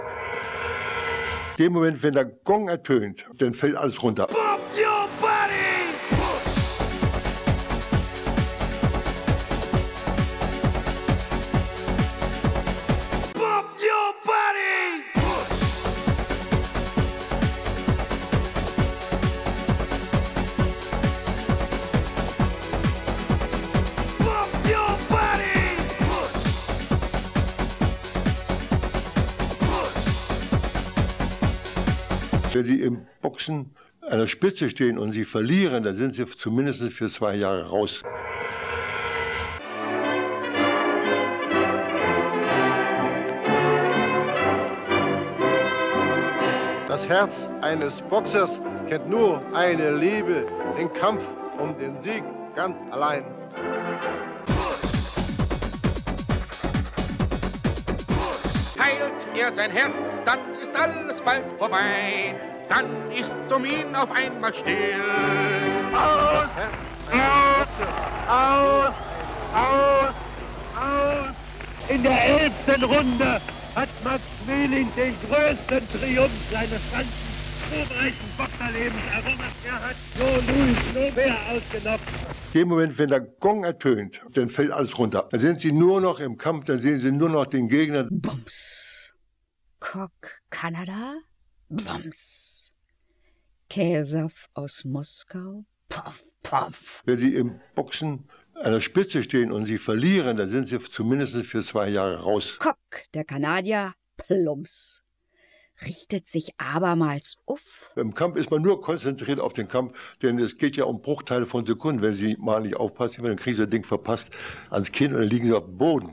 Max Schmeling erzählt aus seinem Leben
Max Schmeling (Sprecher)
Ein Interview mit dem berühmten Boxweltmeister, der sich in seinen letzten Lebensjahren von jeglicher Öffentlichkeit und Medienpräsenz zurückgezogen hatte.
Die Produktion verbindet Interviewpassagen mit Gedichten verschiedener Autoren, wie Goethe, Baudelaire oder Ringelnatz.